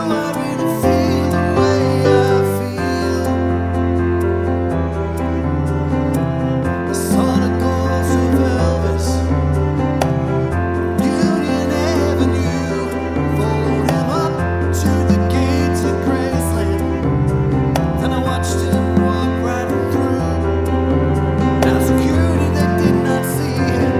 Multiplex Lead Version